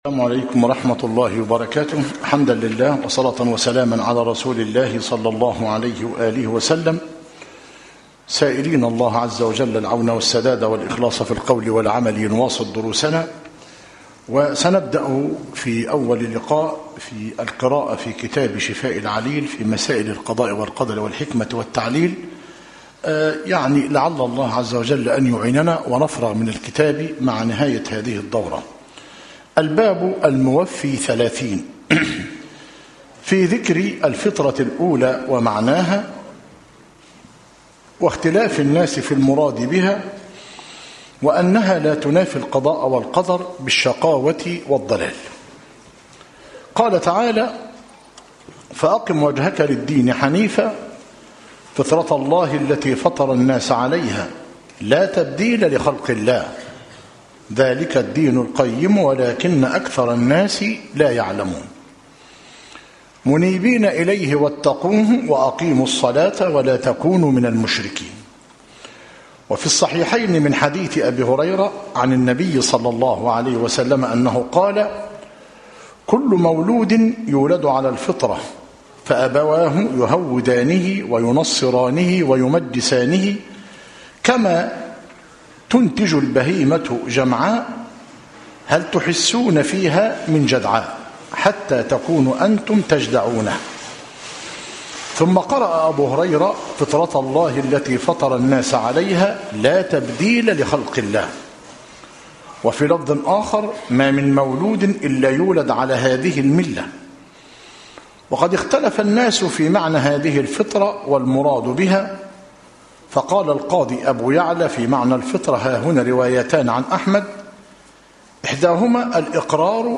كتاب شفاء العليل للعلامة ابن القيم رحمه الله - مسجد التوحيد - ميت الرخا - زفتى - غربية - المحاضرة الثانية والتسعون - بتاريخ 7- شعبان - 1437هـ الموافق 14 - إبريل - 2016 م